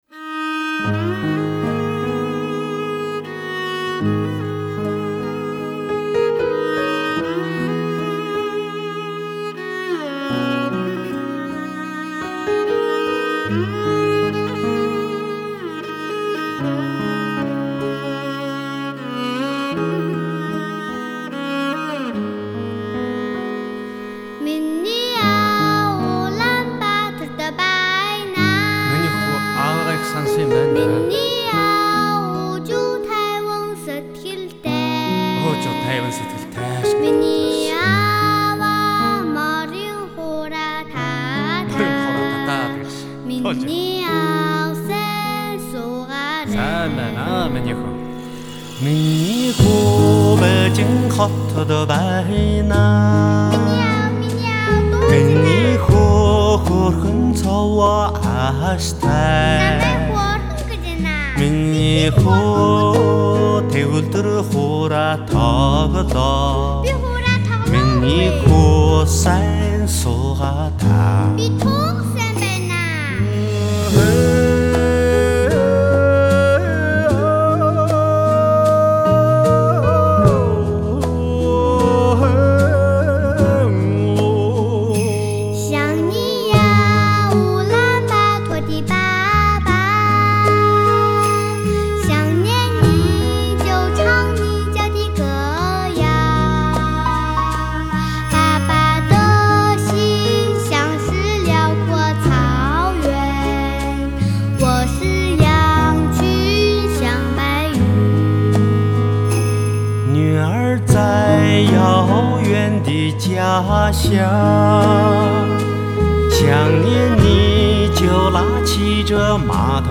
她歌声略带伤感
没有娇嗔、没有羞涩、没有迟疑，她把从祖母那里、从妈妈那里学来的民歌、小调、童谣、儿歌全部唱出来，她的嗓音坚定，充满自信。